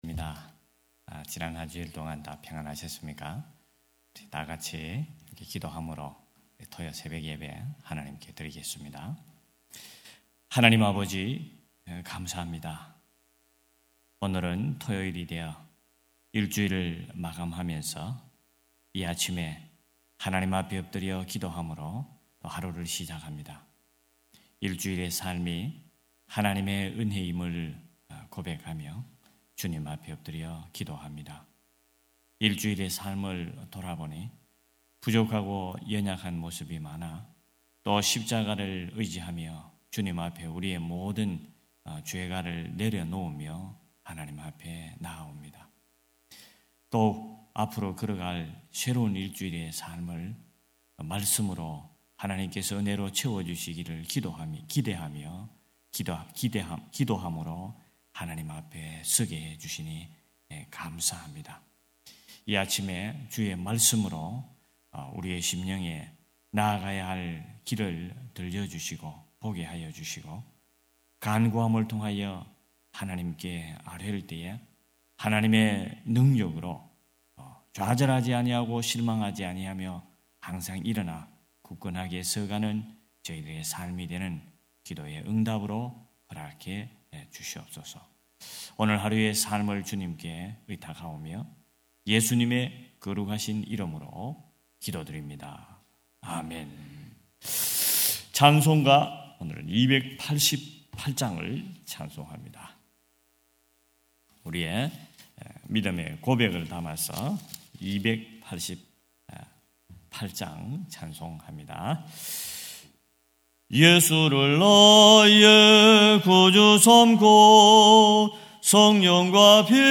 9.13.2025 새벽예배 갈라디아서 6장 1-2절